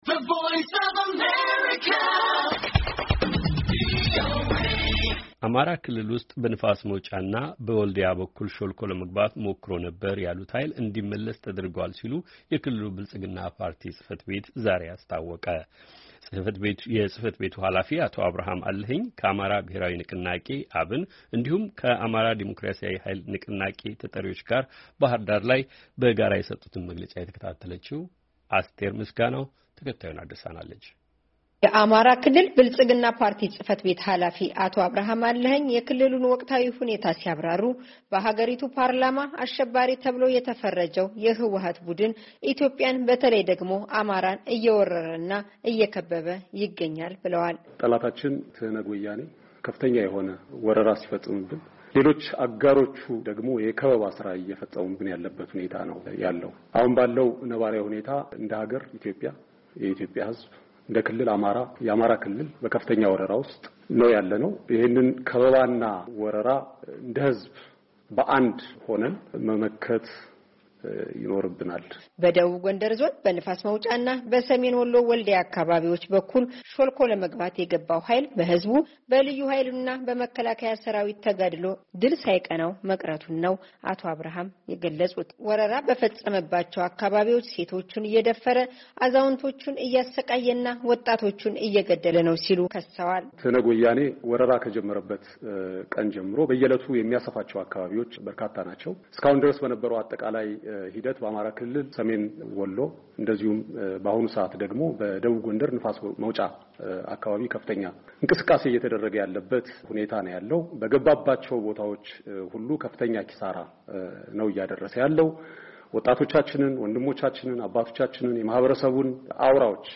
የአማራ ብልጽግና ፓርቲ፣ የአማራ ብሔራዊ ንቅናቄ (አብን) እና የአማራ ዴሞክራሲያዊ ኀይል ንቅናቄ (አዴኃን) መግለጫ